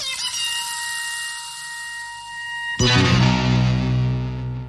Play, download and share Gundam sfx 0079 original sound button!!!!
gundam-sfx-0079.mp3